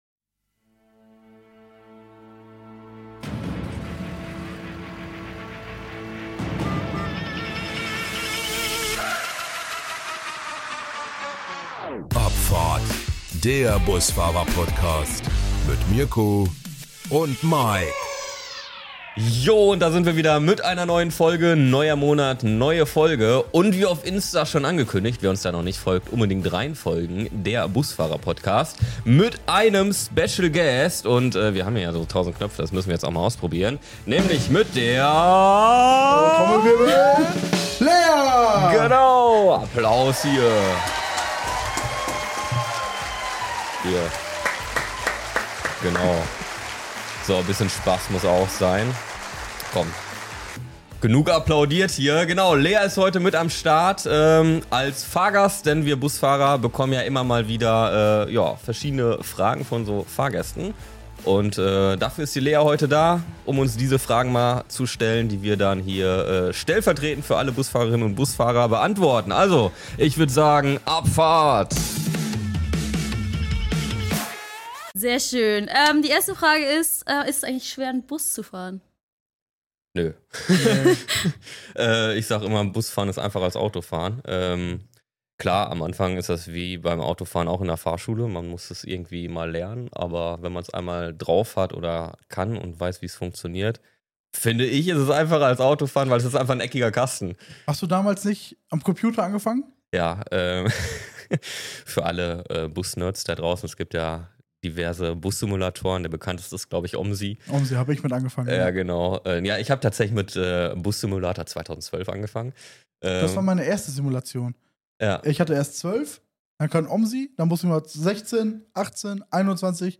Wir reden offen, ehrlich und mit Humor über alles, was Fahrgäste schon immer wissen wollten – von Lieblingsstrecken bis zu peinlichen Momenten.